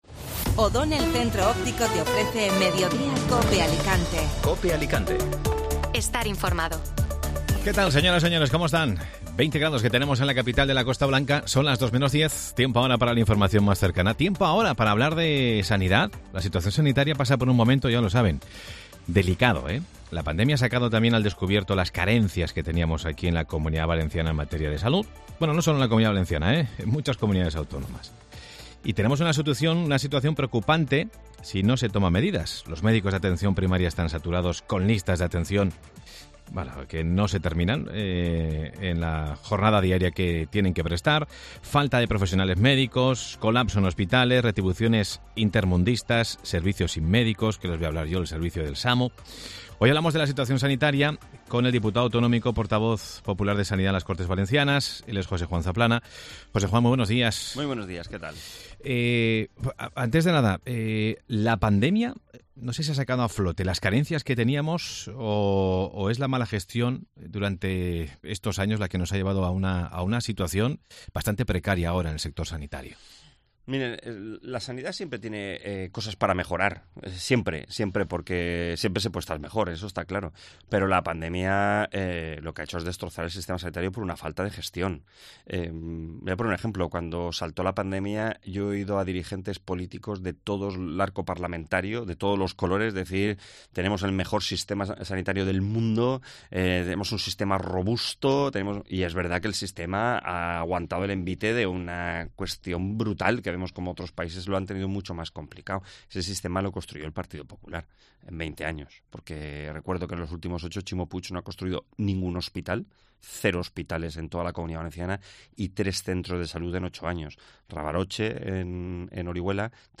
Escucha la entrevista completa en Mediodía COPE Alicante.